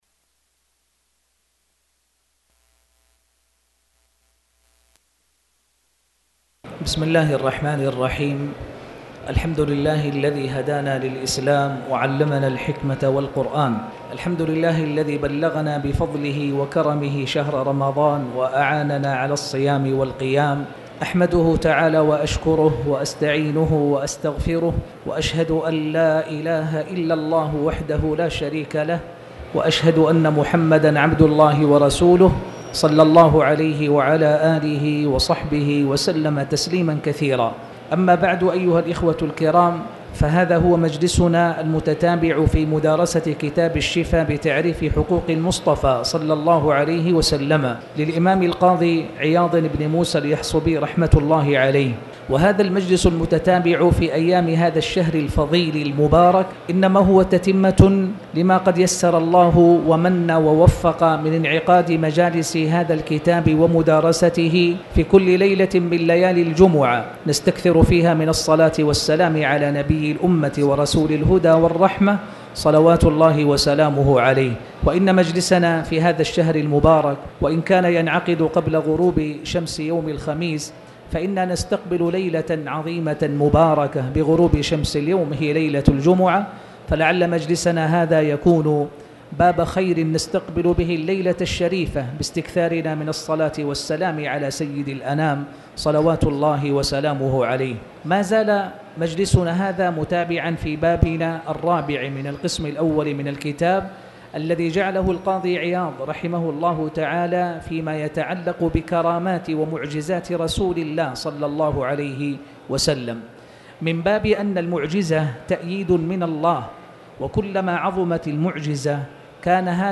تاريخ النشر ٦ رمضان ١٤٣٨ هـ المكان: المسجد الحرام الشيخ